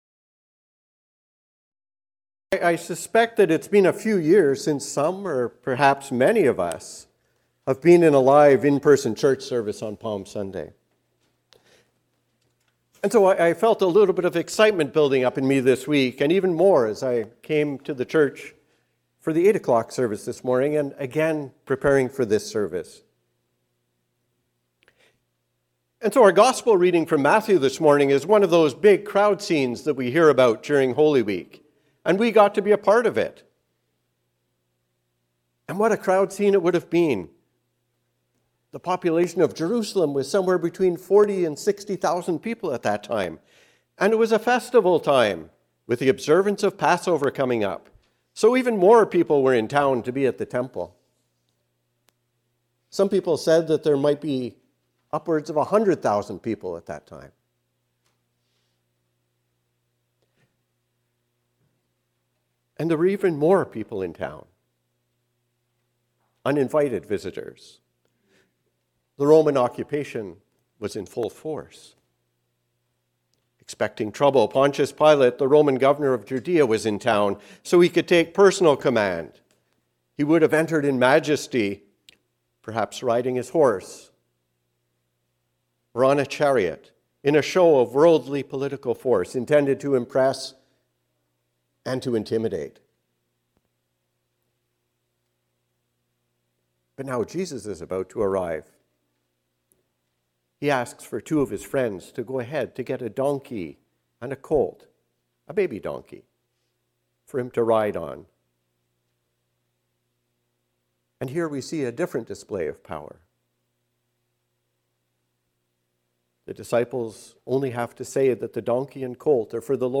Hosanna! A Sermon on Palm Sunday.